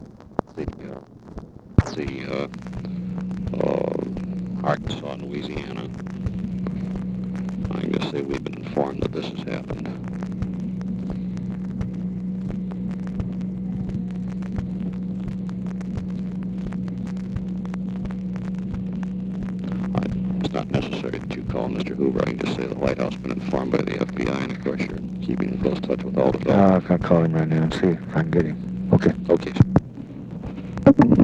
Conversation with GEORGE REEDY, June 29, 1964
Secret White House Tapes